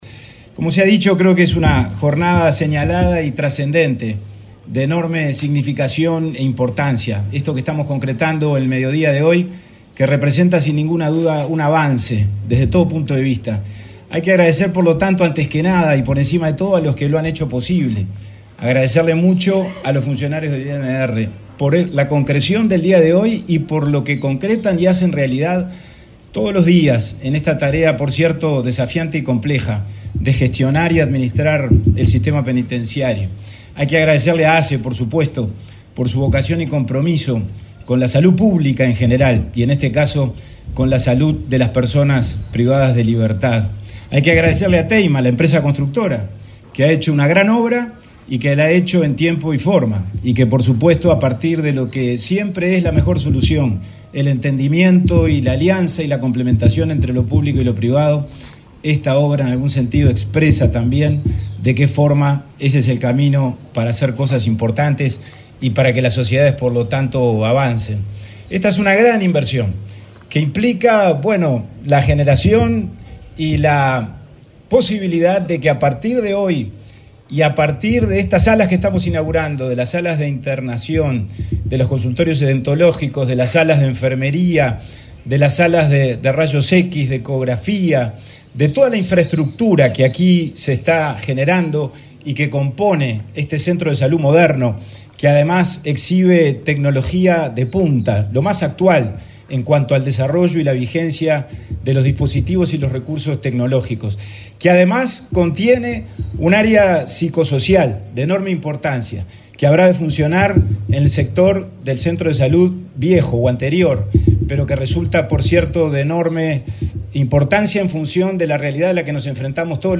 Palabras del subsecretario del Interior, Pablo Abdala | Presidencia Uruguay
Palabras del subsecretario del Interior, Pablo Abdala 22/11/2024 Compartir Facebook X Copiar enlace WhatsApp LinkedIn El subsecretario del Interior, Pablo Abdala, se expresó, durante el acto de inauguración del centro de salud Unidad No. 4, de Santiago Vázquez, en Montevideo.